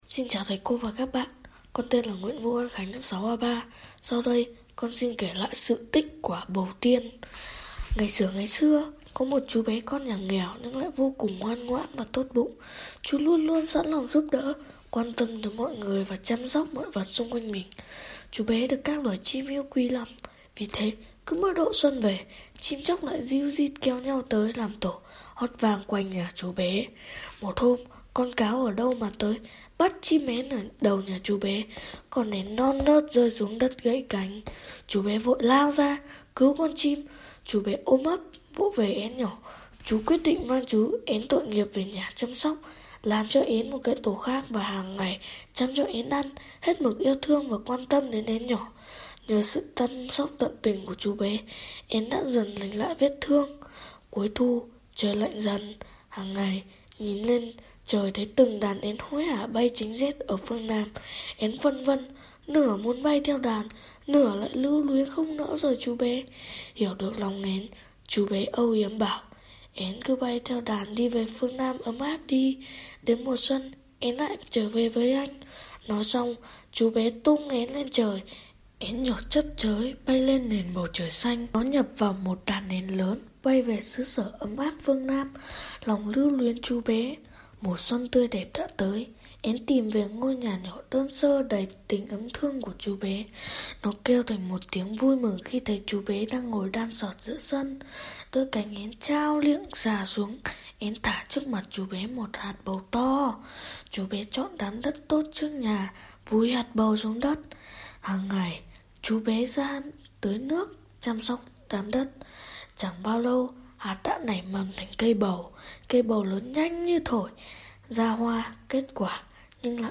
Sách nói | Sự tích quả bầu tiên